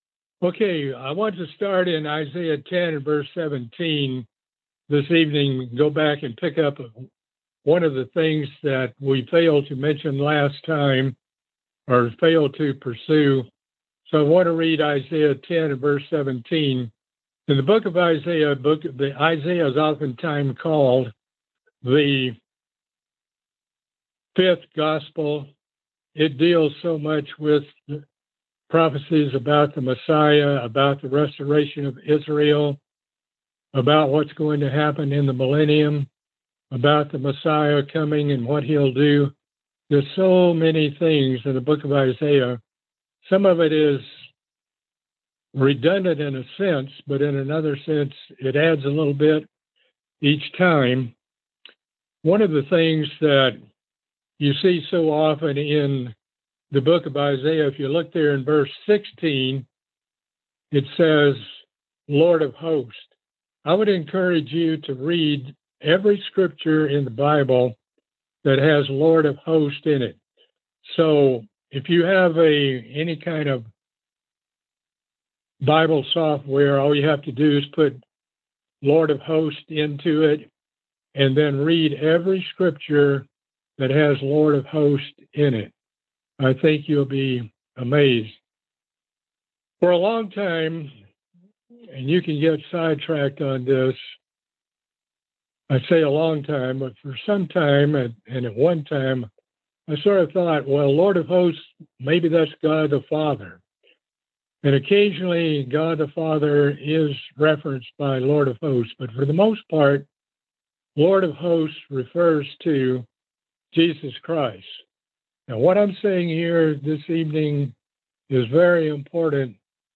Book of Isaiah Bible Study - Part 10